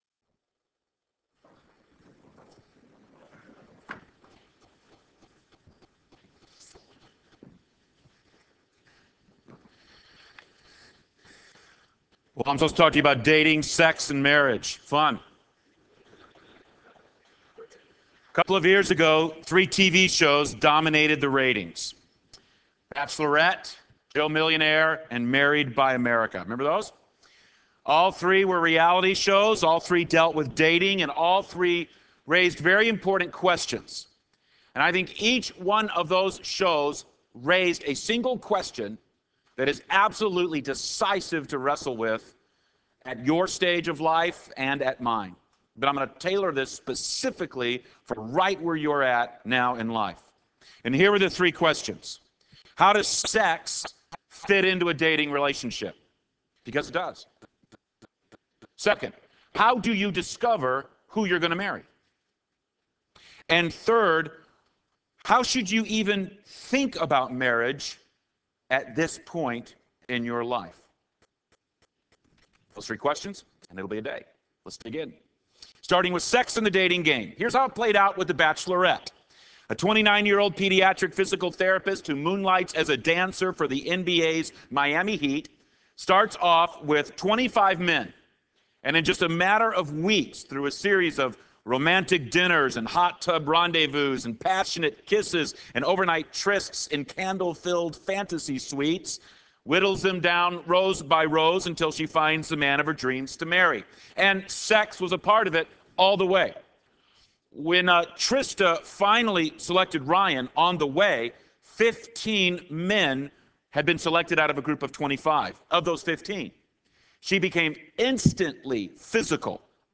N.C. Address: Principles for building healthy relationships.